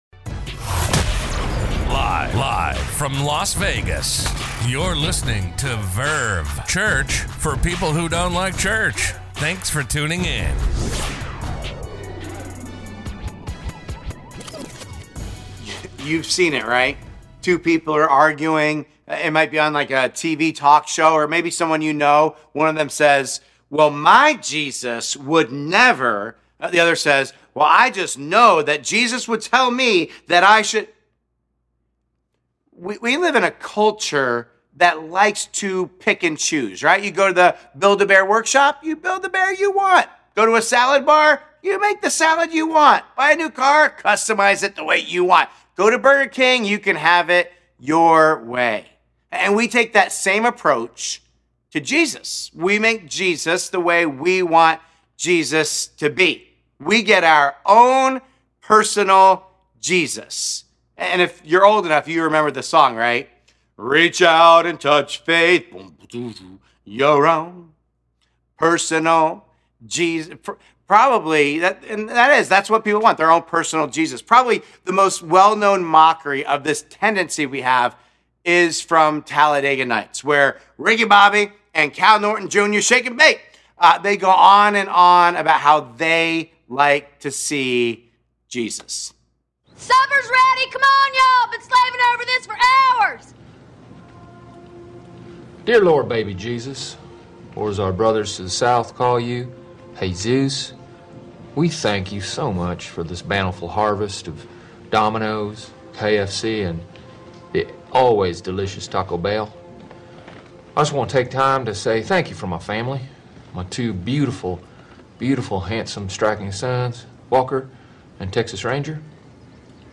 A message from the series "Not God Enough.."